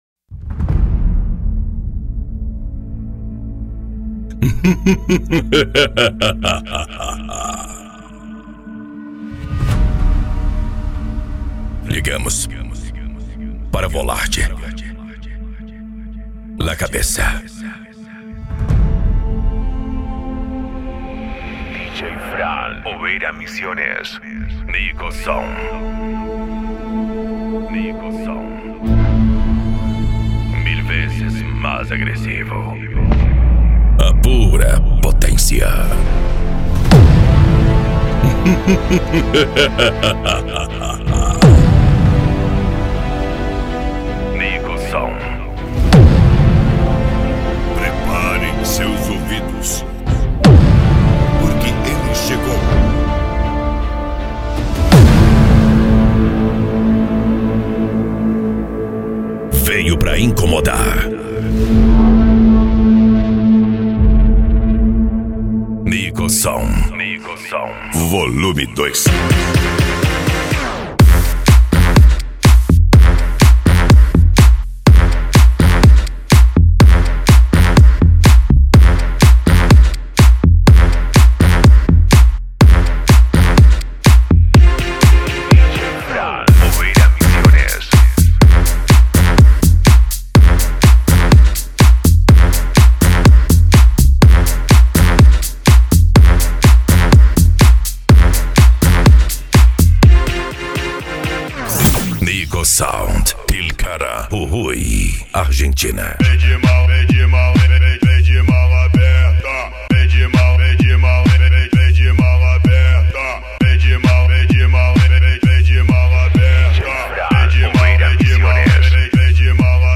Bass
Electro House
Eletronica
Psy Trance
Remix